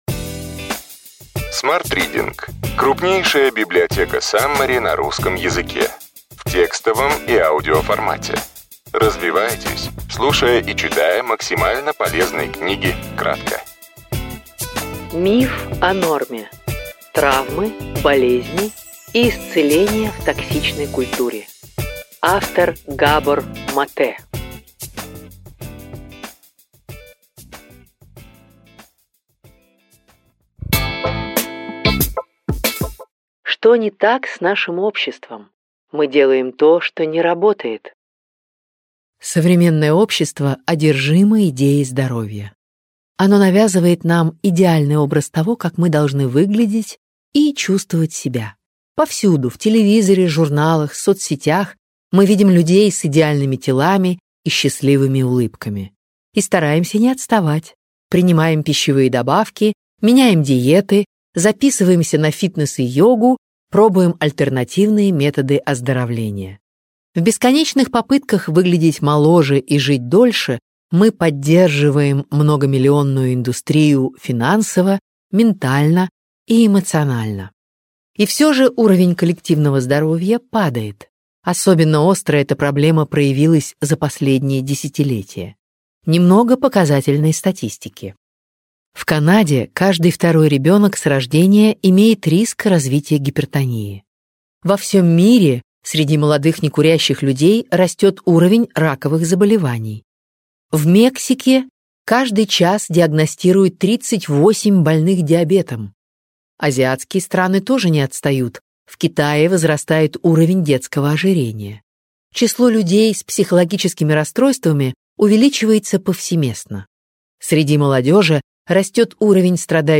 Аудиокнига Миф о норме. Травмы, болезни и исцеление в токсичной культуре.